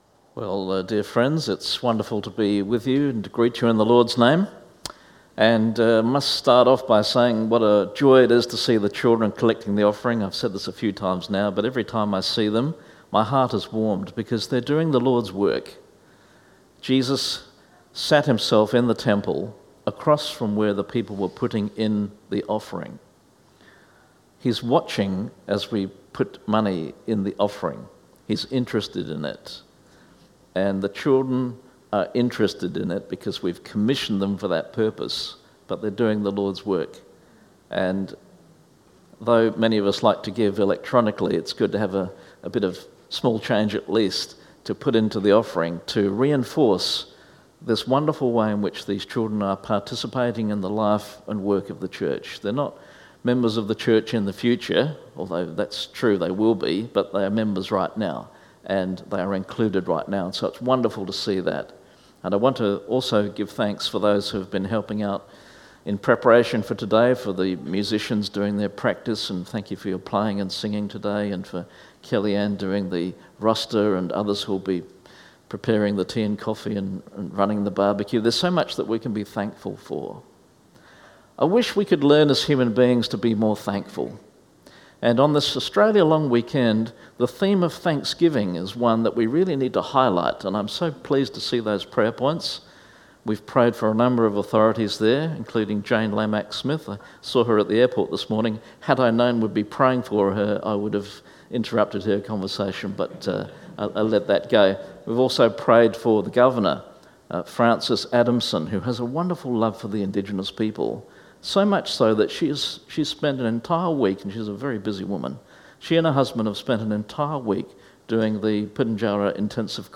Bible Text: Ephesians 2:11-8 | Preacher